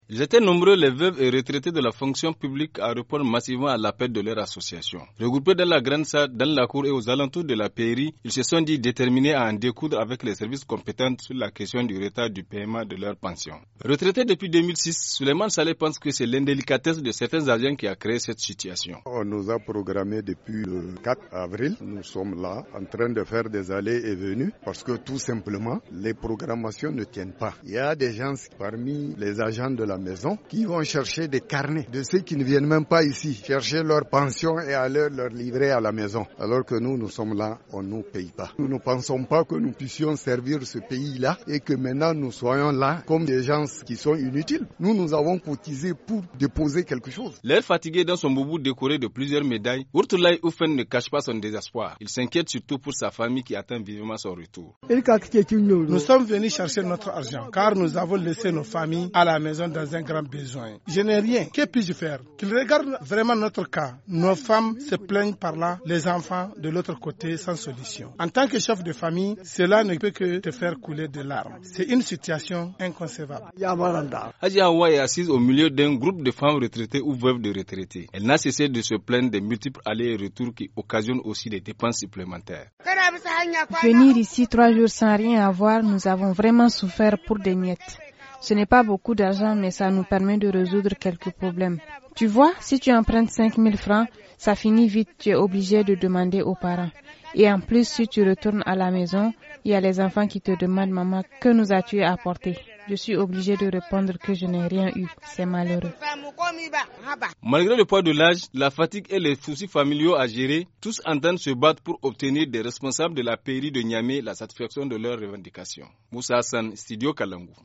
Magazine en français